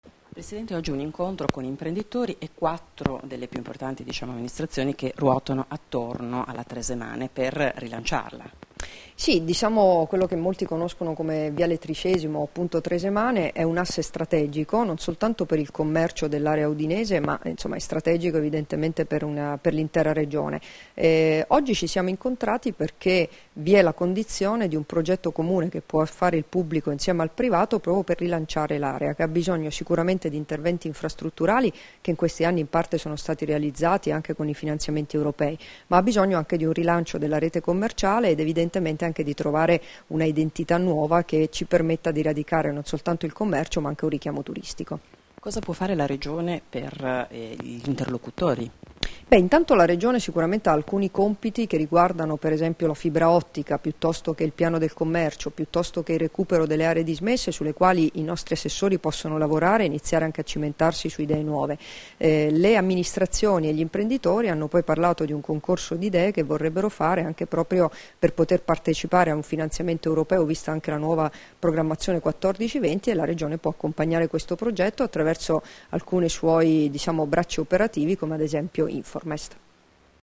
Dichiarazioni di Debora Serracchiani (Formato MP3) [1284KB]
all'incontro con sindaci e amministratori dei Comuni rivieraschi di viale Tricesimo, rilasciate a Udine il 28 luglio 2015